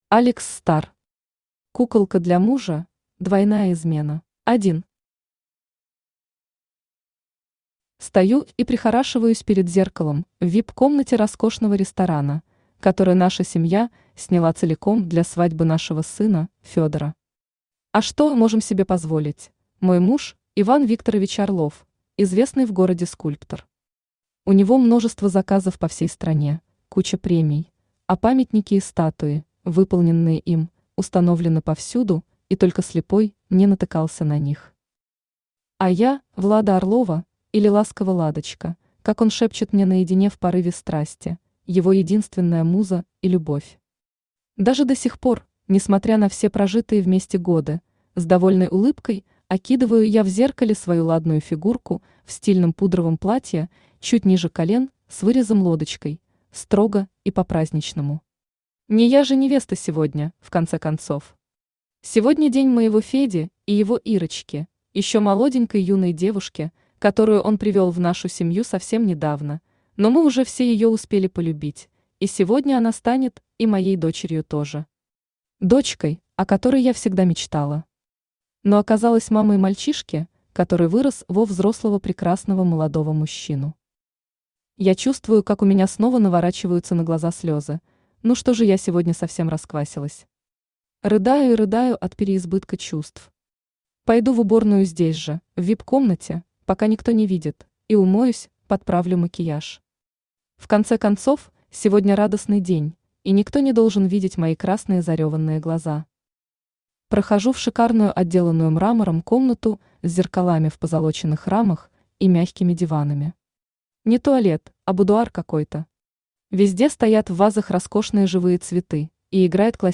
Аудиокнига Куколка для мужа: двойная измена | Библиотека аудиокниг
Aудиокнига Куколка для мужа: двойная измена Автор Алекс Стар Читает аудиокнигу Авточтец ЛитРес.